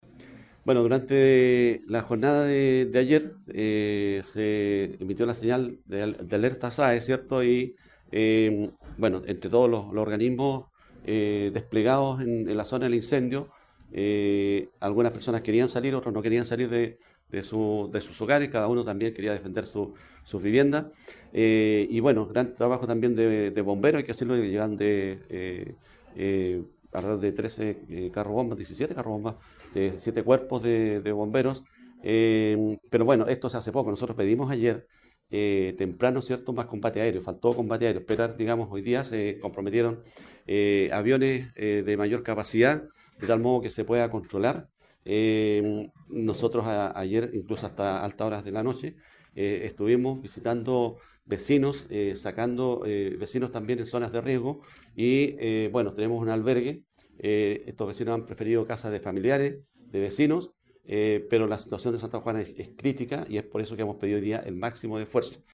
El alcalde de Santa Juana, Ángel Castro, compartió el balance de la comuna en medio del complejo escenario.